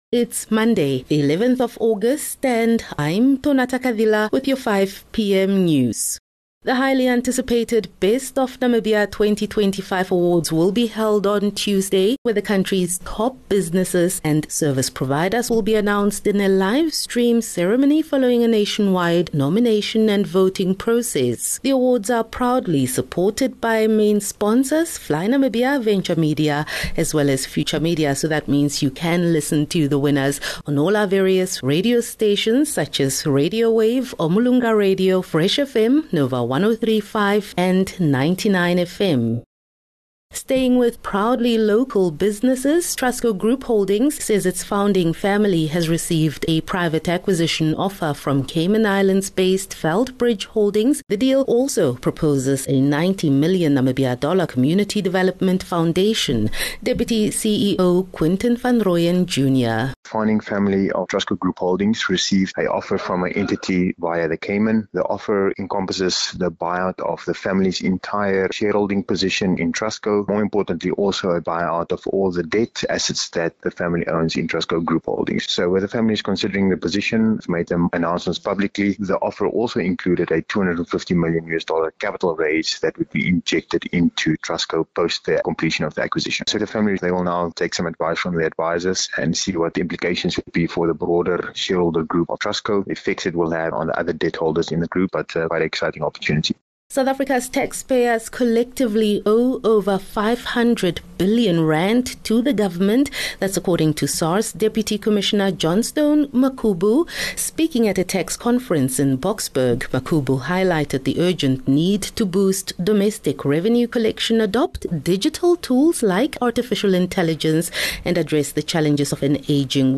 11 Aug 11 August - 5 pm news